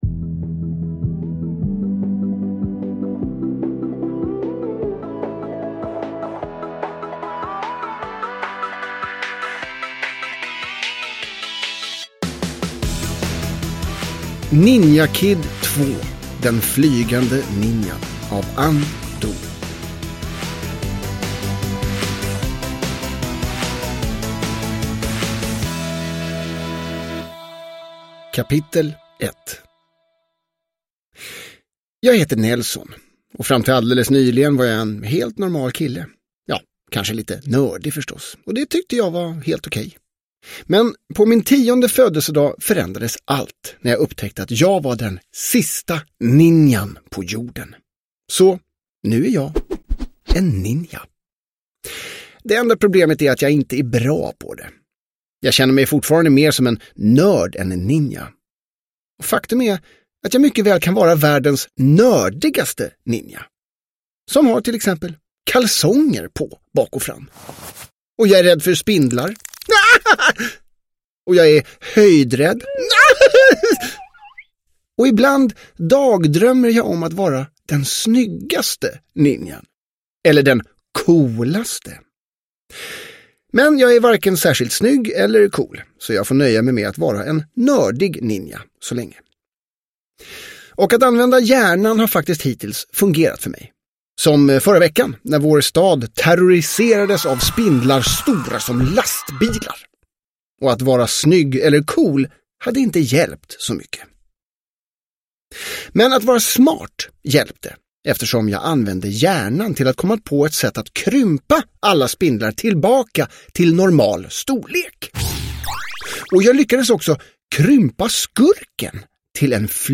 Den flygande ninjan – Ljudbok – Laddas ner